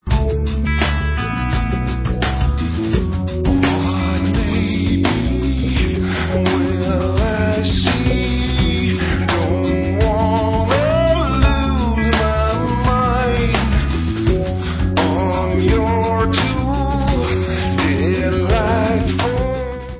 Allstar dutch rockband w/ex-anouk & kane members